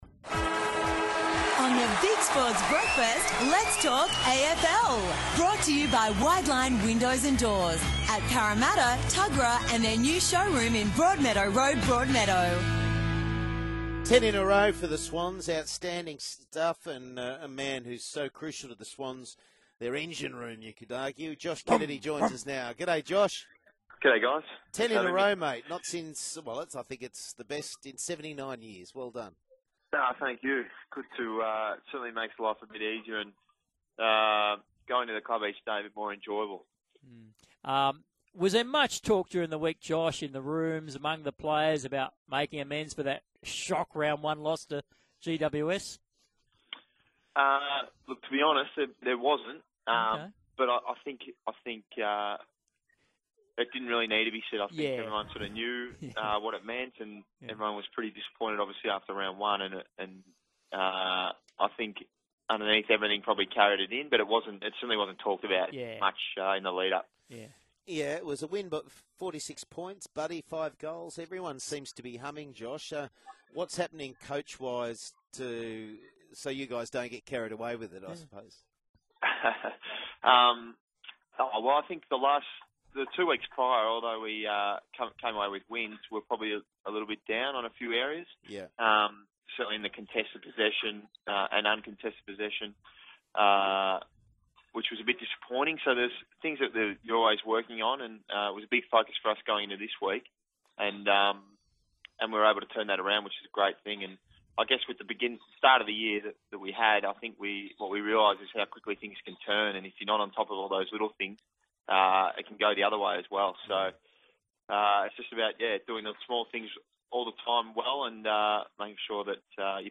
Sydney Swans midfielder Josh Kennedy appeared on Sky Sports Radio's Big Sport Breakfast program on Monday June 30, 2014